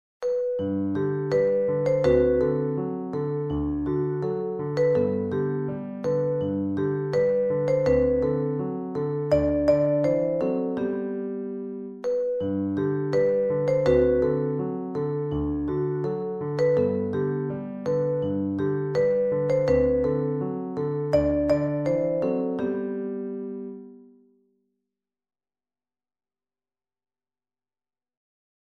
Een Engels kringspel voor kinderen vanaf 7 à 8 jaar